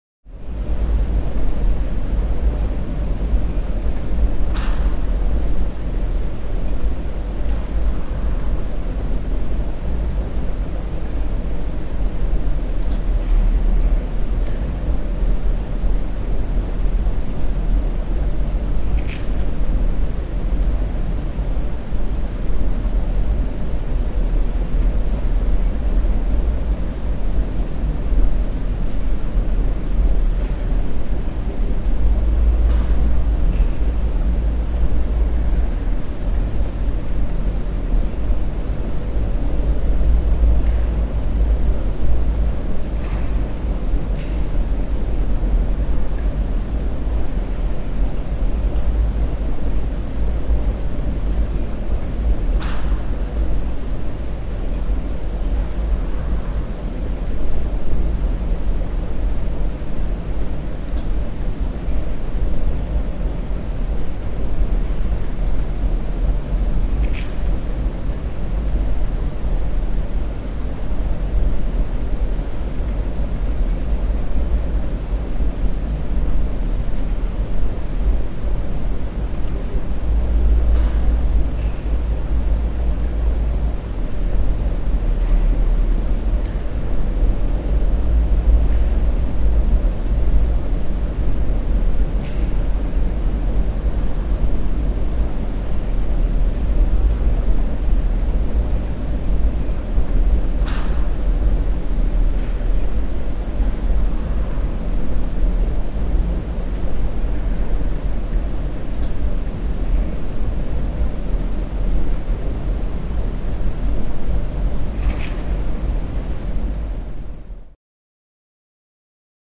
Room Tone | Sneak On The Lot
Basic Large Stone Room Tone With Occaisional Stoney Drips